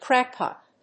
/ˈkræˌkpɑt(米国英語), ˈkræˌkpɑ:t(英国英語)/
アクセント・音節cráck・pòt 《口語》